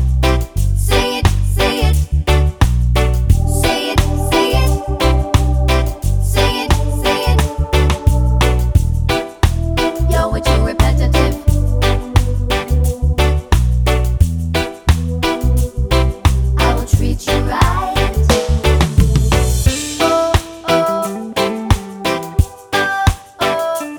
no Backing Vocals Reggae 3:34 Buy £1.50